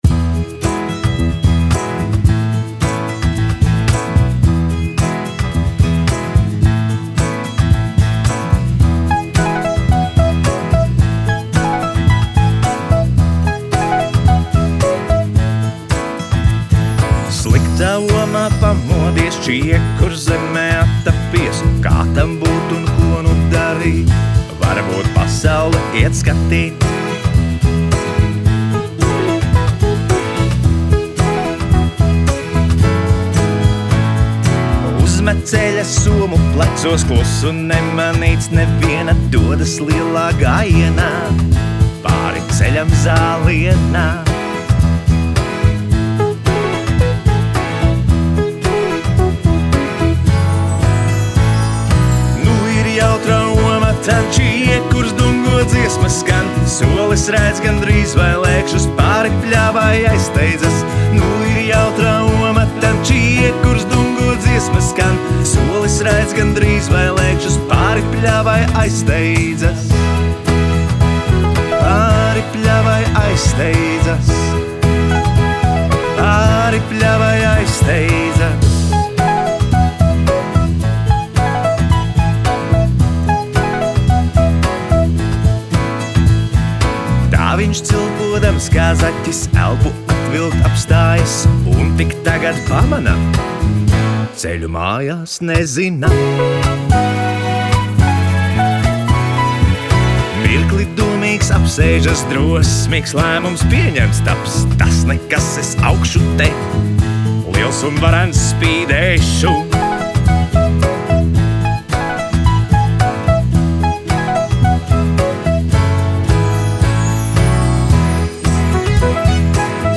Dziesmas un dziesmu pavadījumi.
taustiņi
ģitāra
perkusijas.